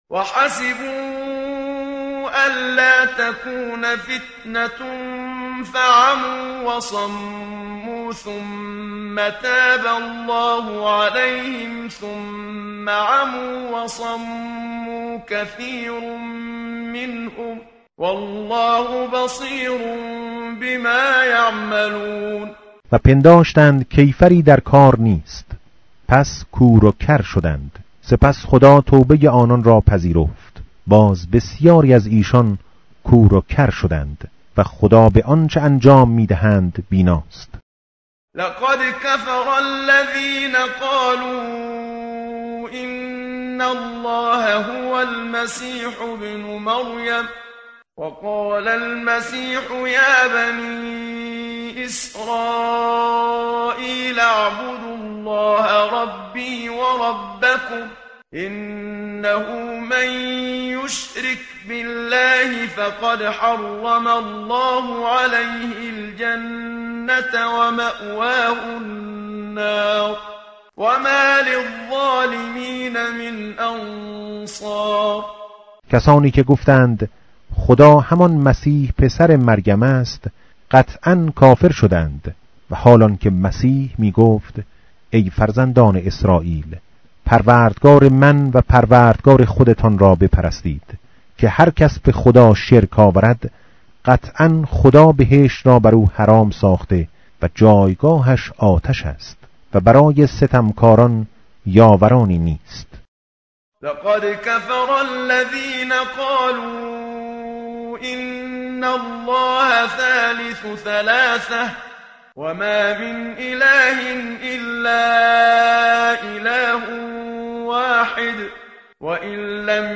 tartil_menshavi va tarjome_Page_120.mp3